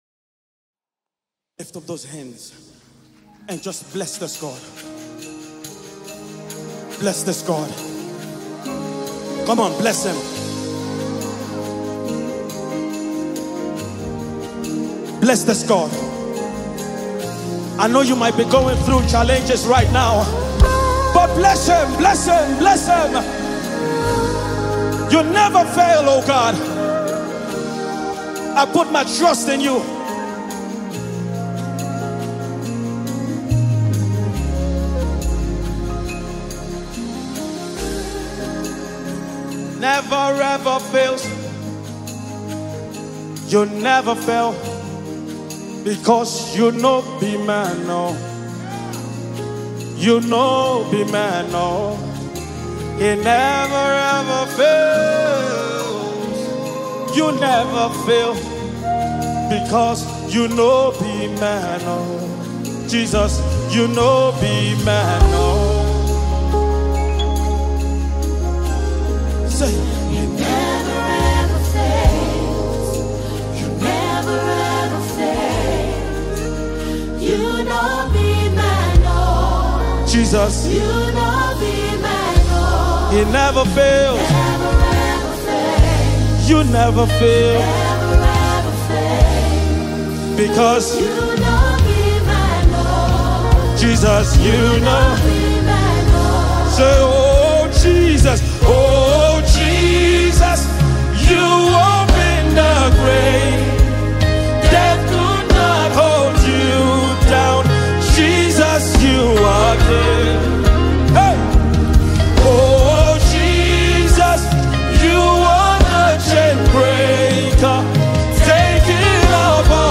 is a Nigerian gospel artist based in South Africa.
spirit-filled worship song